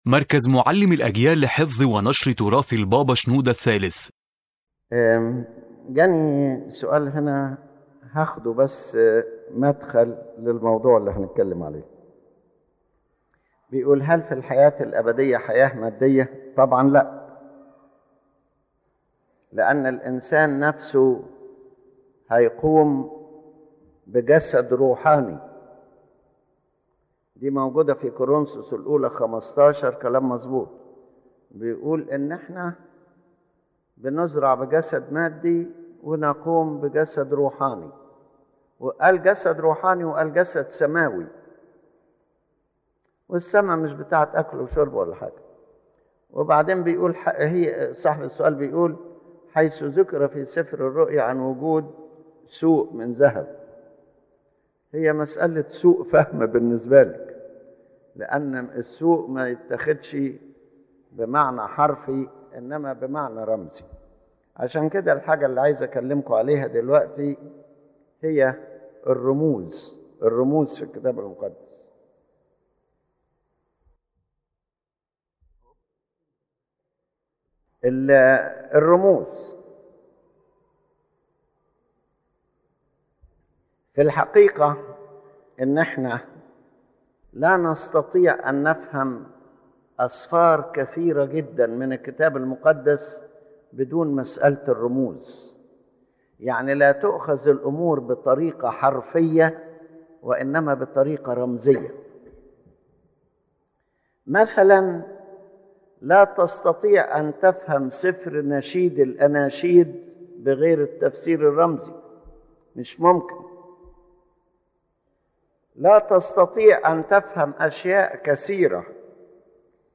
His Holiness Pope Shenouda III speaks about the importance of symbols in understanding the Holy Bible, explaining that many texts cannot be properly understood if they are taken only literally.